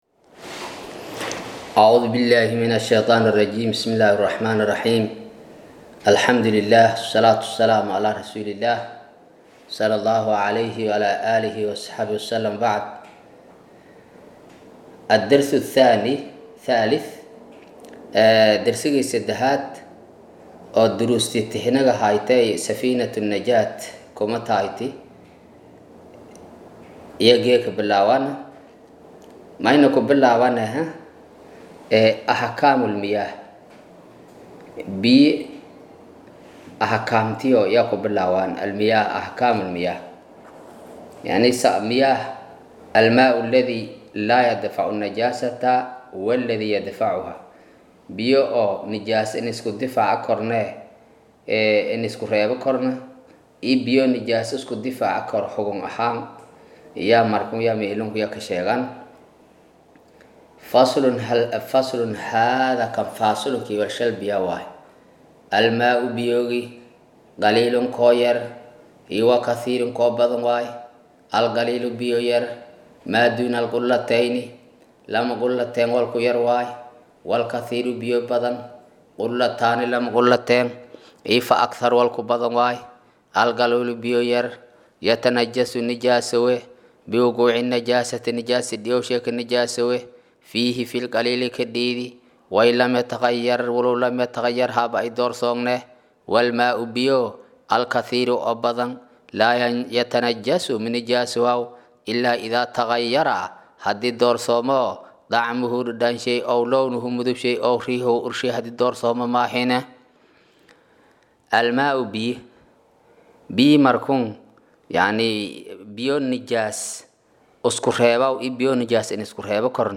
Maqal:- Casharka Safiinatu Najaa “Darsiga 3aad”